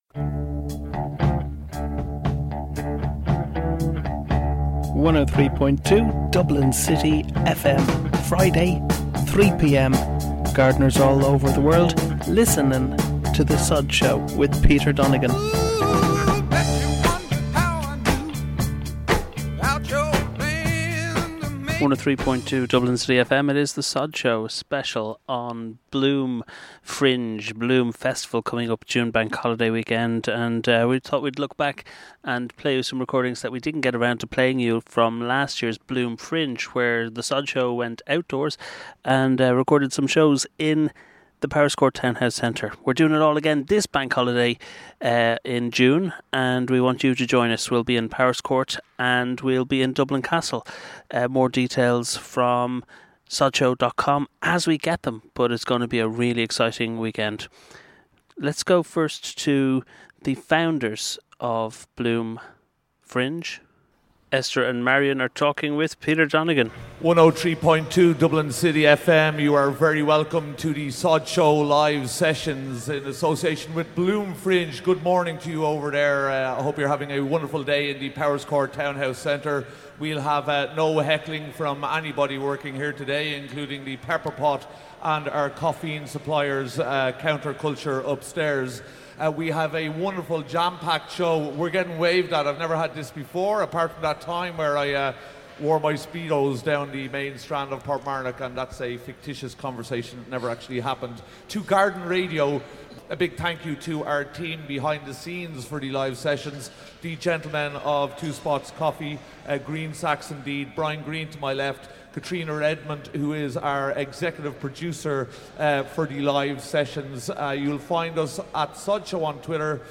Last year The Sodshow Live Sessions as part of Bloom Fringe 2015 aired live from the stunningly beautiful Powerscourt Townhouse Centre.
We recorded of course our chat with them, live.